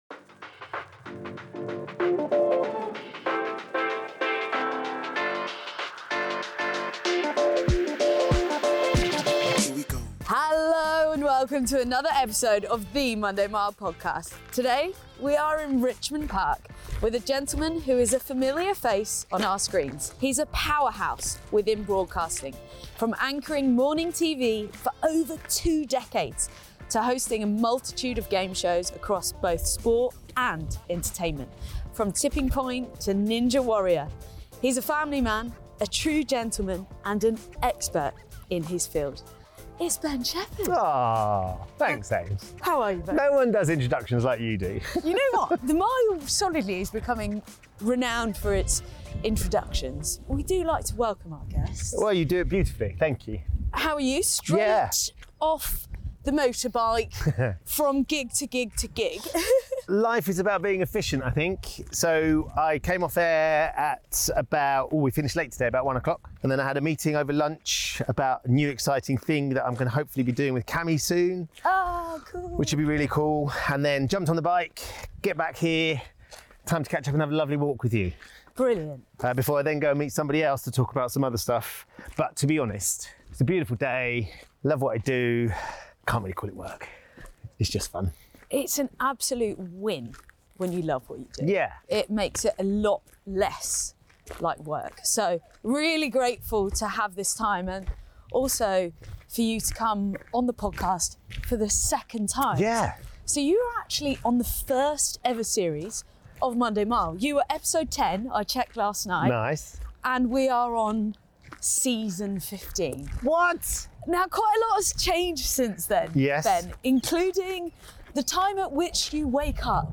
Aimee will meet each of her guests for a walk and a chat and over the course of the mile she will find out how they cope on a Monday morning - their idiosyncrasies, breakfast routines, alarm noises - and how, when they need to, find the motivation to kick-on through the week.